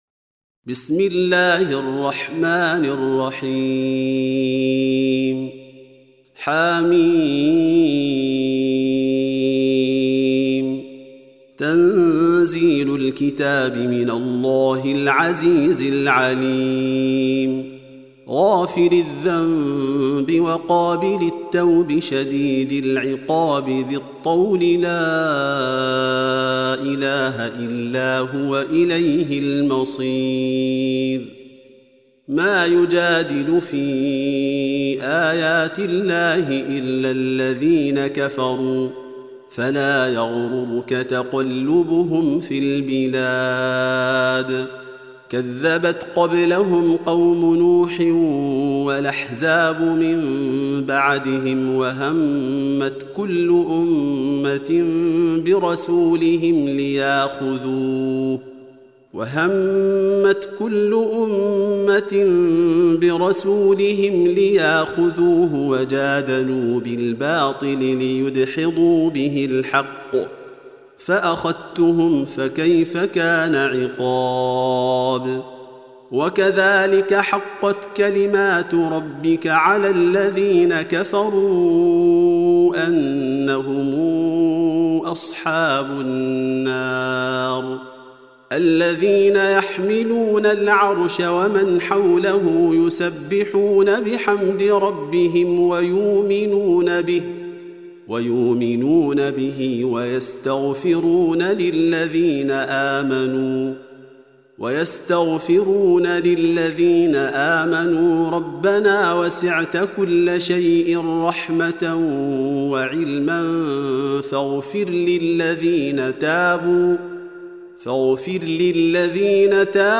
Surah Repeating تكرار السورة Download Surah حمّل السورة Reciting Murattalah Audio for 40. Surah Gh�fir سورة غافر N.B *Surah Includes Al-Basmalah Reciters Sequents تتابع التلاوات Reciters Repeats تكرار التلاوات